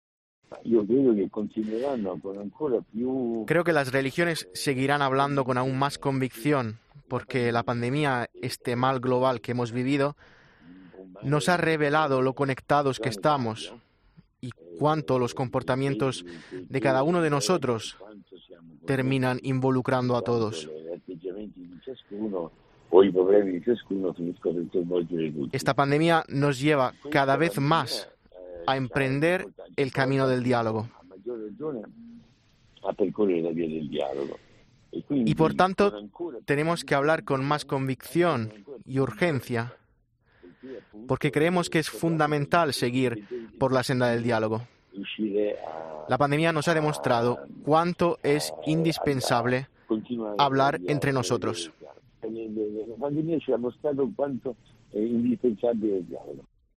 ECCLESIA tuvo la oportunidad de hablar el pasado mes de octubre con el arzobispo de Bolonia sobre varios temas antes del Encuentro Internacional organizado por Sant'Egidio en Roma